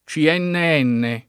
vai all'elenco alfabetico delle voci ingrandisci il carattere 100% rimpicciolisci il carattere stampa invia tramite posta elettronica codividi su Facebook C.N.N. [ © i $ nne $ nne ; ingl. S& i en $ n ] n. pr. f. (in it.)